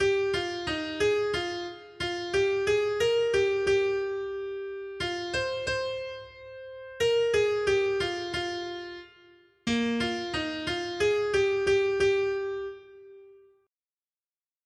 Noty Štítky, zpěvníky ol495.pdf responsoriální žalm Žaltář (Olejník) 495 Skrýt akordy R: Plesejte Bohu, který nám pomáhá. 1.